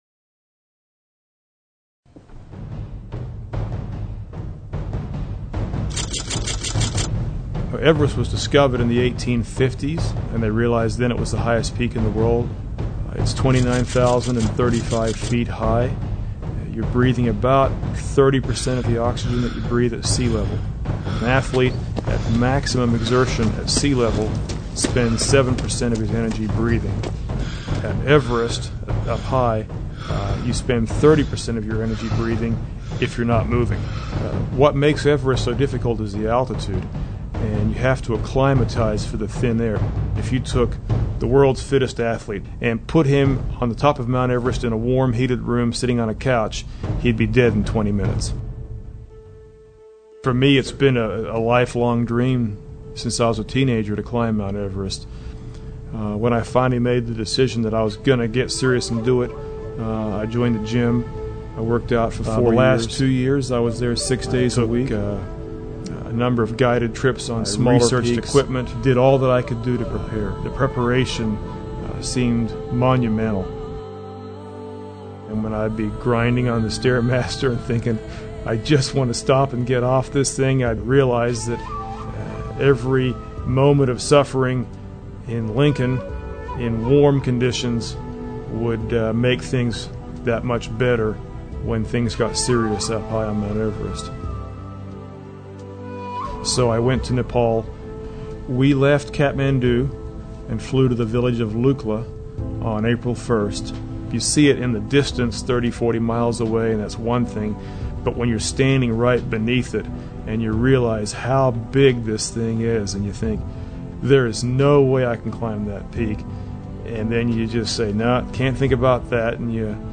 Sermon: Embracing Trials